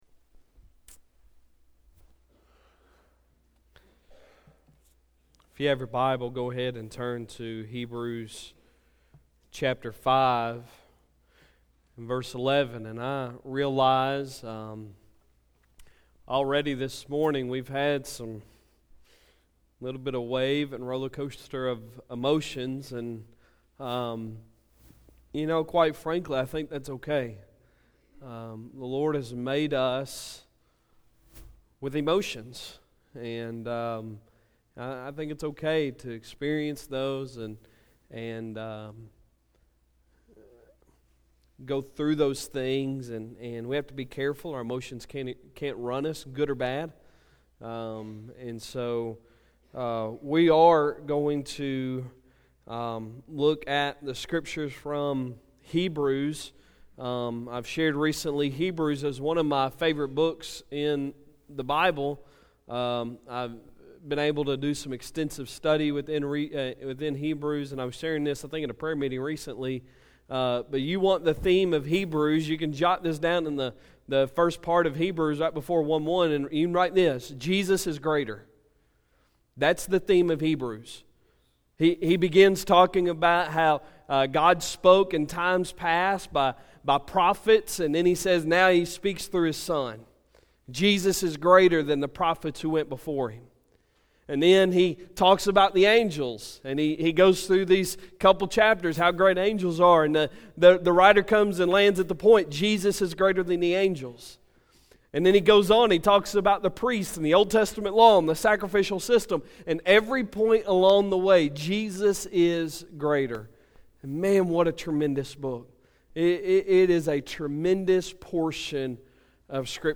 Sunday Sermon December 30, 2018
Morning Worship